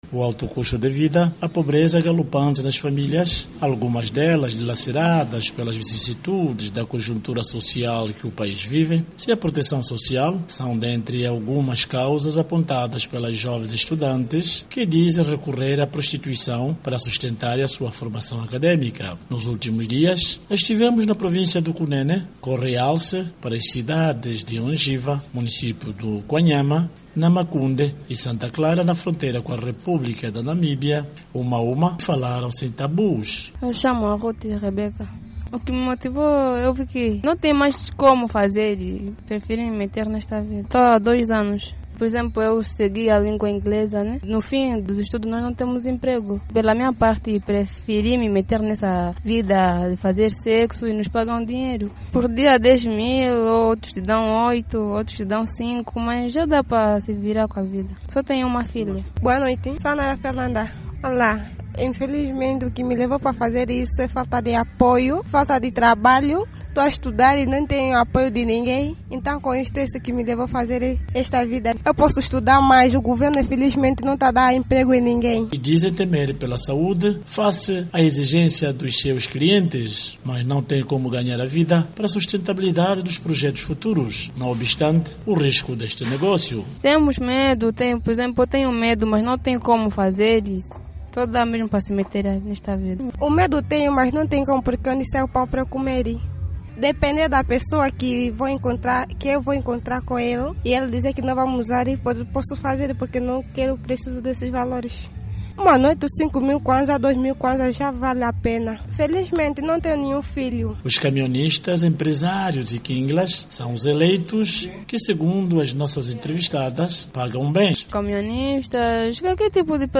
A VOA falou com várias jovens nas cidades de Ondjiva, Kwanhama, Namacunde e Santa Clara, na província angolano de Cunene.